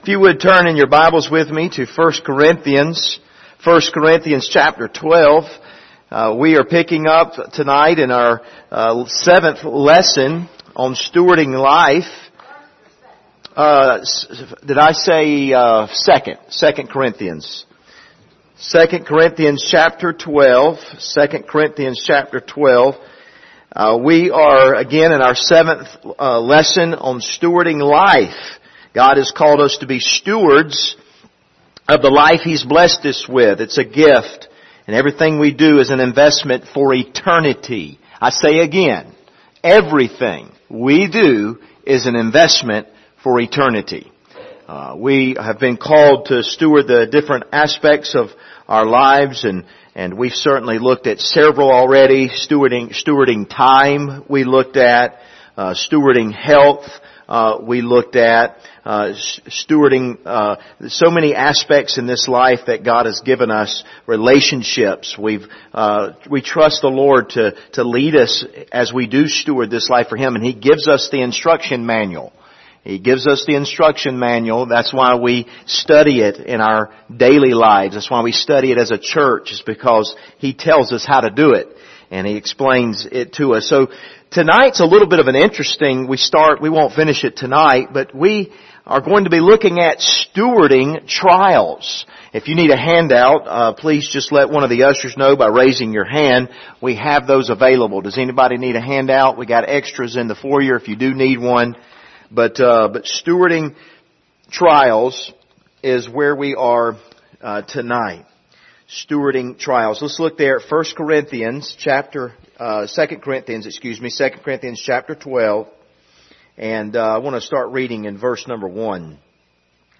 Passage: 2 Corinthians 12:2-4 Service Type: Wednesday Evening Topics